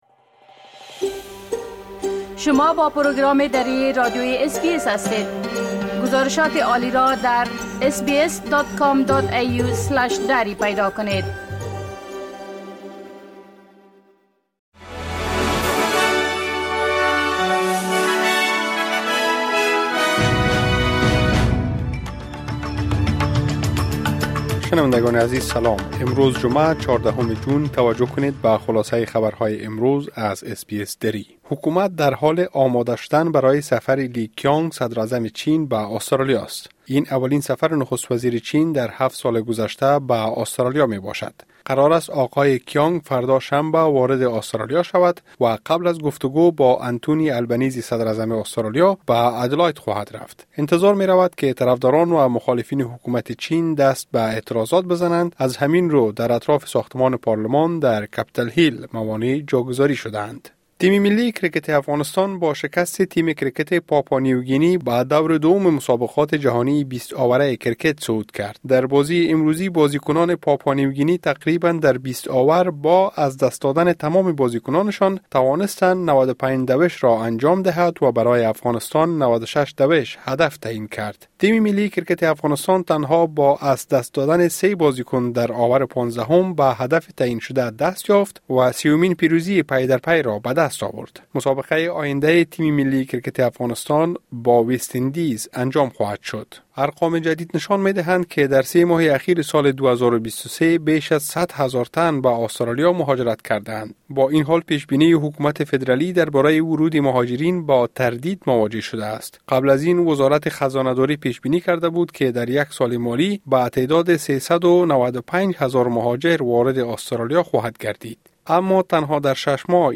خلاصۀ مهمترين خبرهای روز از بخش درى راديوى اس بى اس|۱۴ جون ۲۰۲۴